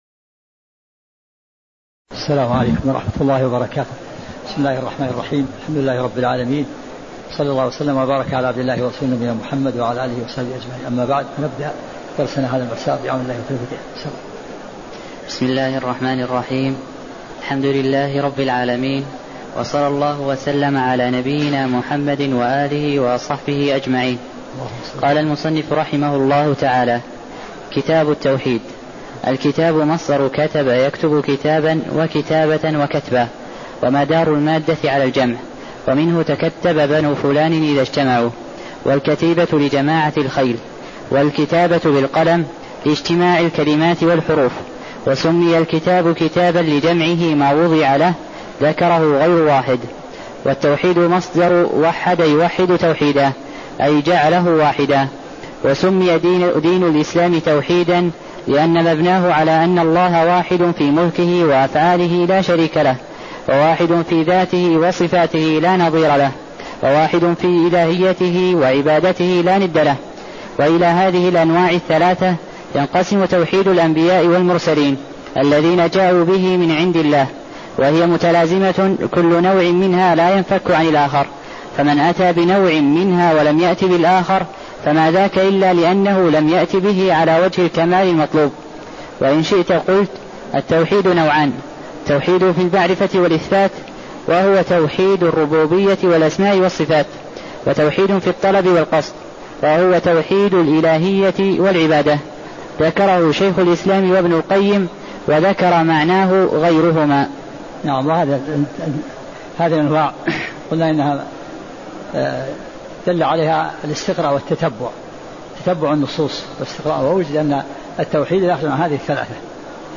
تاريخ النشر ٣٠ شعبان ١٤٣٦ هـ المكان: المسجد النبوي الشيخ